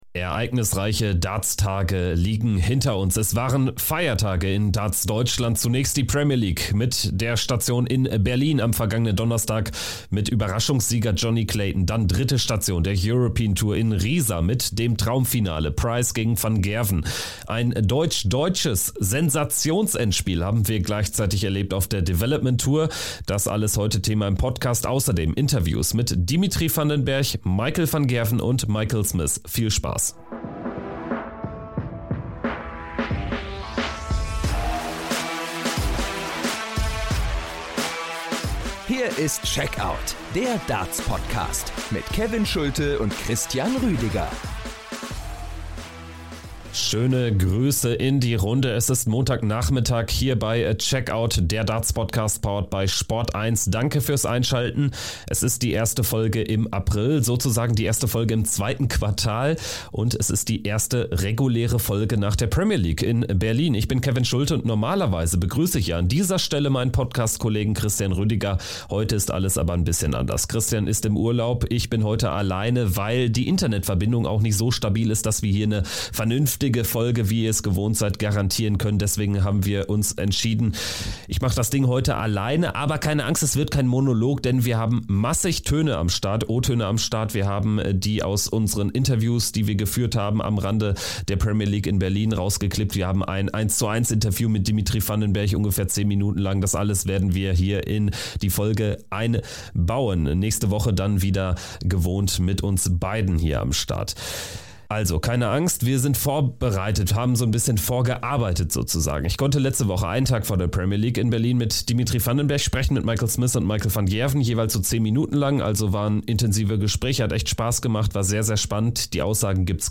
Michael van Gerwen erklärt im exklusiven Interview, wie er seine Form derzeit einschätzt, was er von seinen Konkurrenten Gerwyn Price und Peter Wright hält und welche Turniere er in diesem Jahr auslassen wird. Michael Smith berichtet, wie stressig der Alltag als erstmaliger Weltmeister ist, wie selten er seit seinem Titelgewinn in den eigenen vier Wänden war, auf welches Turnier er sich am meisten freut und welches Event sein ...